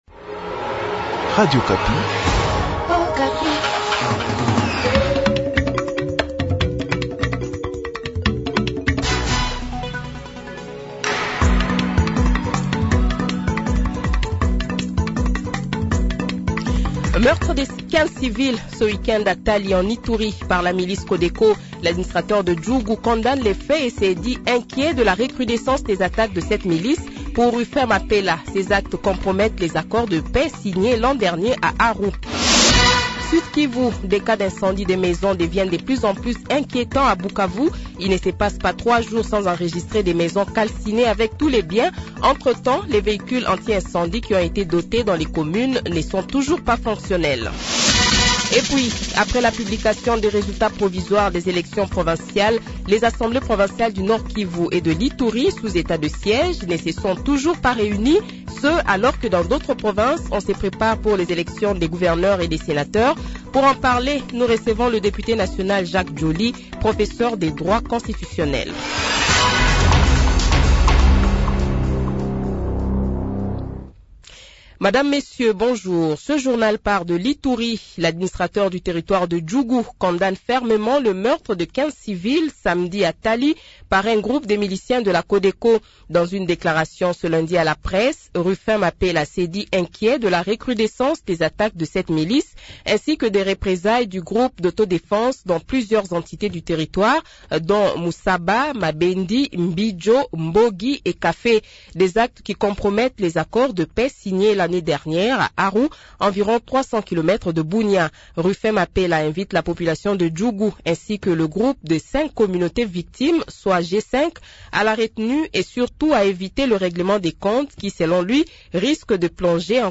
Journal Francais Midi
Le Journal de 12h, 19 Fevrier 2024 :